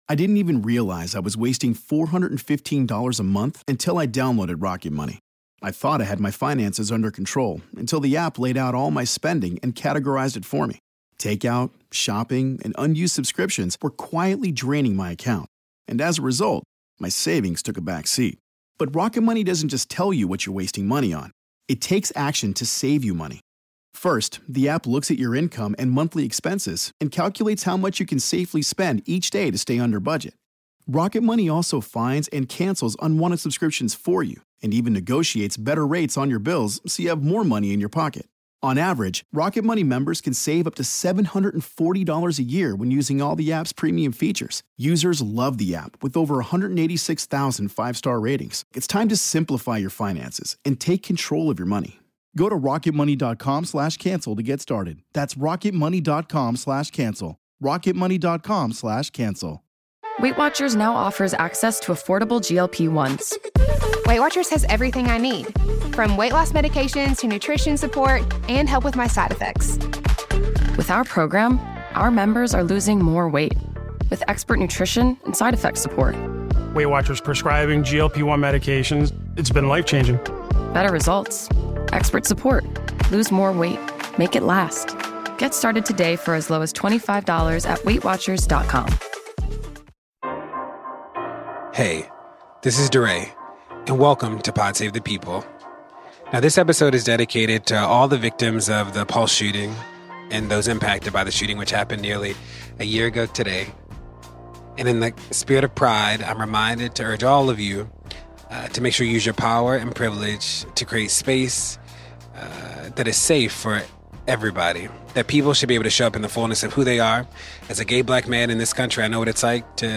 DeRay sits down for a candid conversation with Katy Perry in person about race, cultural appropriation, allyship, political tension in her family and what Katy’s doing to grown and learn.